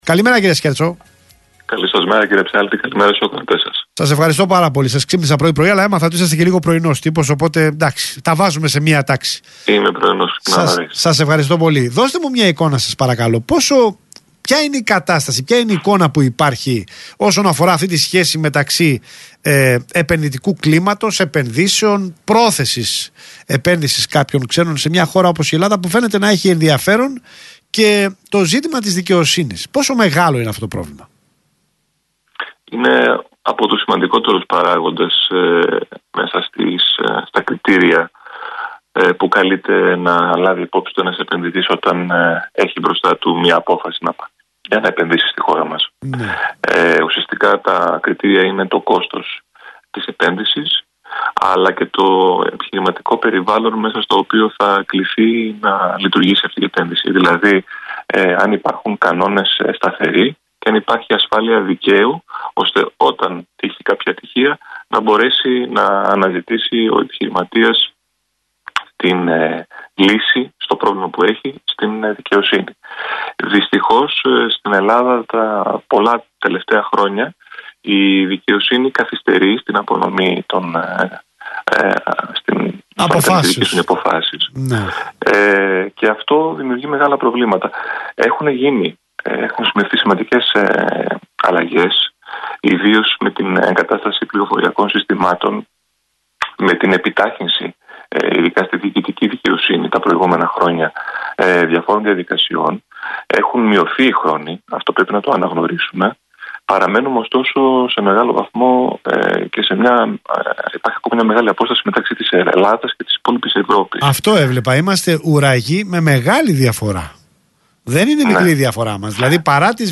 Συνέντευξη του Γενικού Διευθυντή του ΣΕΒ, κ. Άκη Σκέρτσου στον Ρ/Σ REAL FM, 14/2/2018